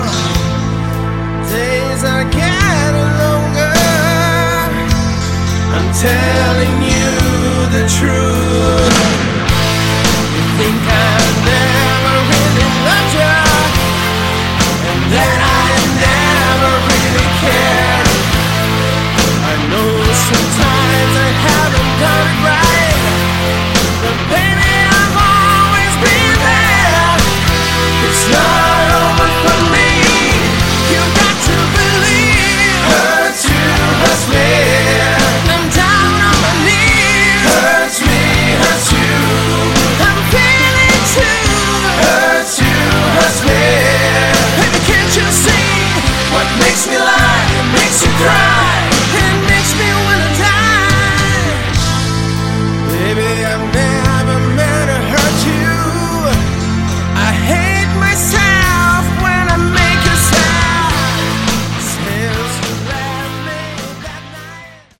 Category: Melodic Hard Rock
guitar, vocals
bass
keyboards
drums